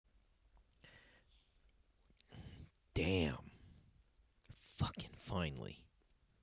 Live from the Thingularity Studios